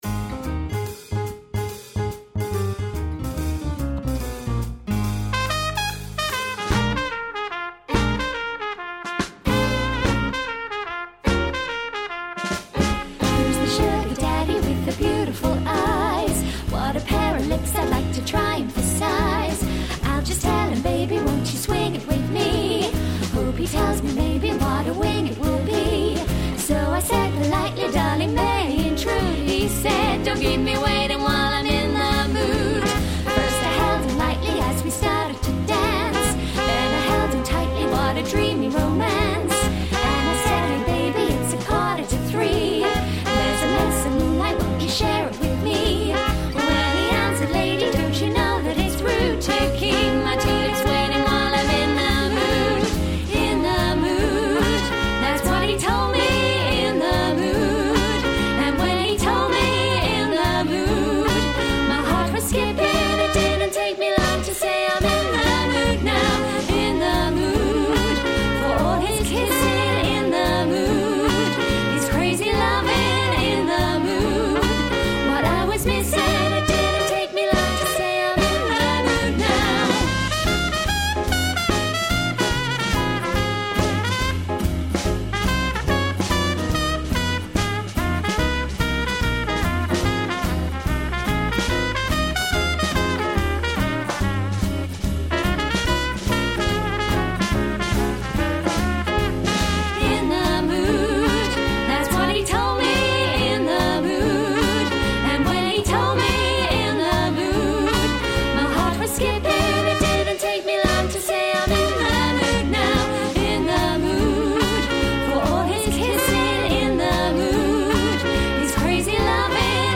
• Fashionable postmodern duo mixing vintage and modern music
2 x Vocal, Backing Tracks